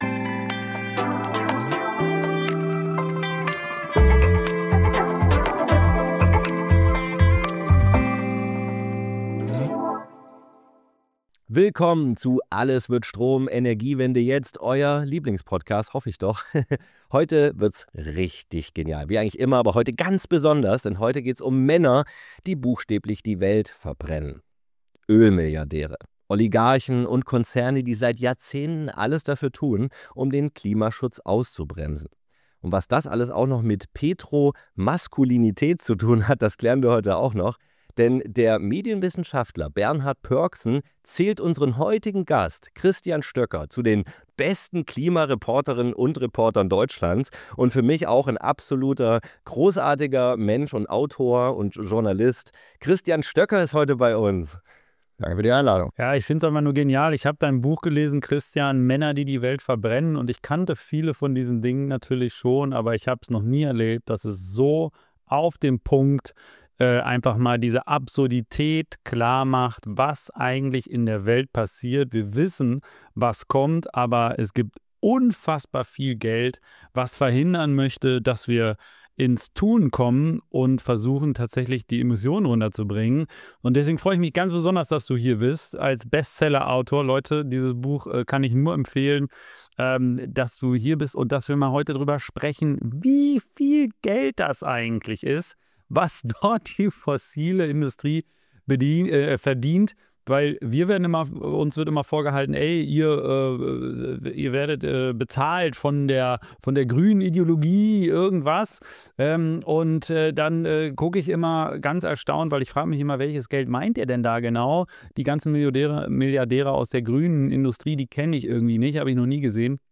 Beschreibung vor 6 Monaten Heute bei Alles wird Strom: Wir sprechen mit Christian Stöcker, Autor von Männer, die die Welt verbrennen. Es geht um Ölkonzerne, Milliardäre und Oligarchen, die seit Jahrzehnten mit unfassbaren Geldmengen gezielt Desinformation streuen, Politik beeinflussen und effektiven Klimaschutz blockieren. Wir reden über fossile Subventionen, Lobbyismus, die Machtstrukturen hinter den Kulissen – und was wir dagegen tun können.